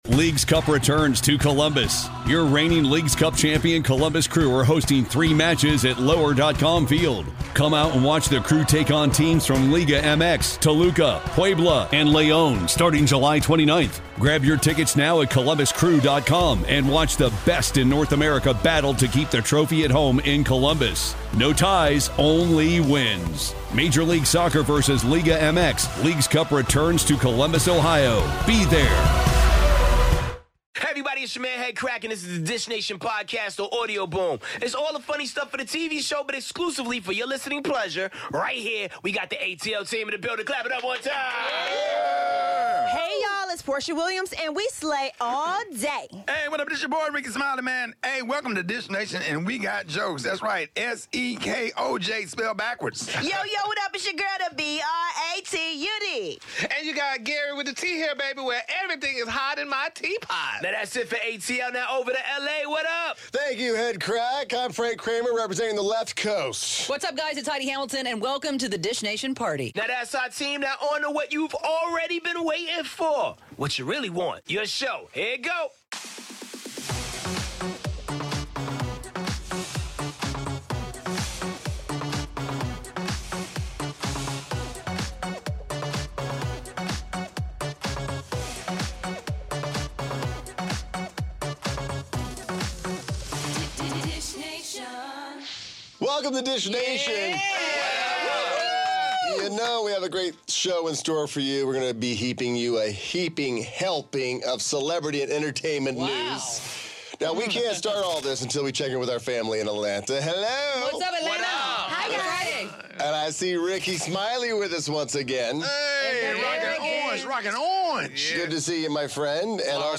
Regina Hall and Marsai Martin swing by the studio to dish on their 'Little' movie 🎥🙌🏽 The 'Real Housewives of Atlanta' reunion preview isn't so peachy 😤 🍑 And Patti LaBelle launches a line of Chinese food 😱 All this and more on today's Dish Nation!